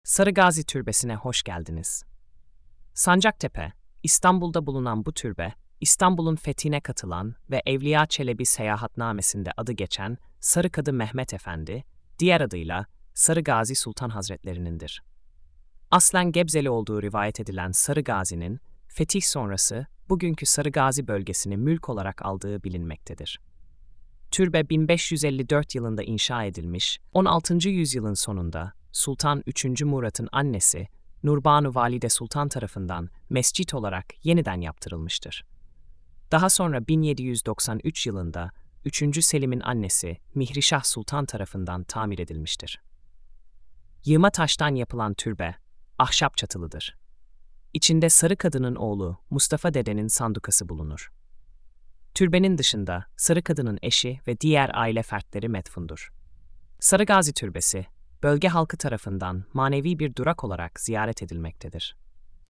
SESLİ ANLATIM: